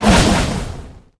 amber_ulti_throw_02.ogg